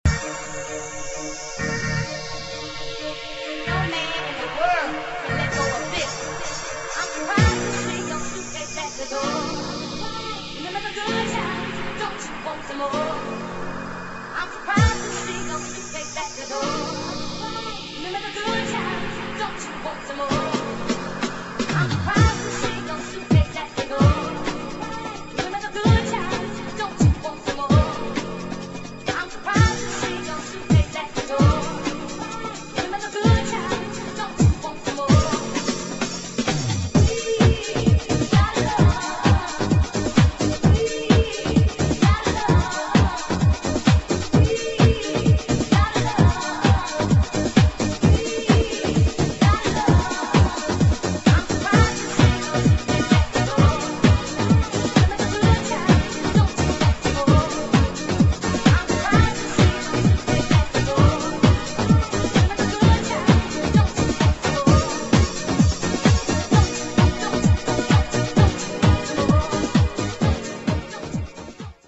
[ HOUSE / GARAGE HOUSE ]